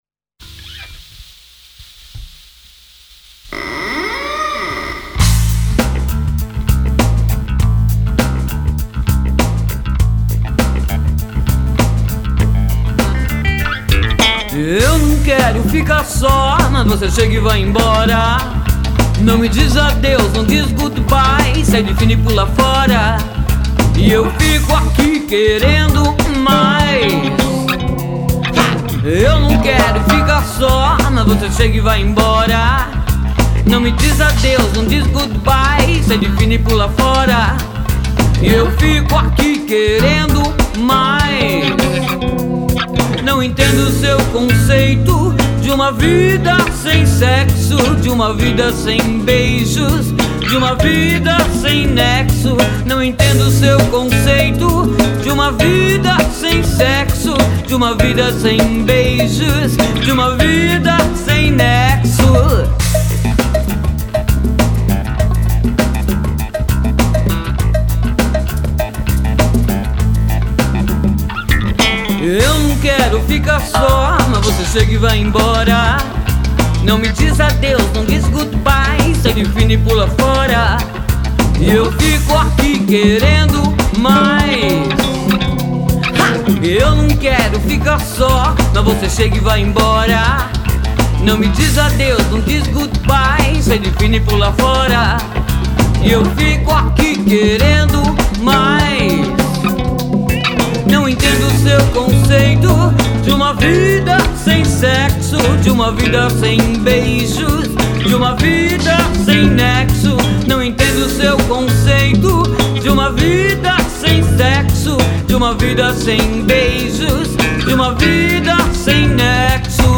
o rock...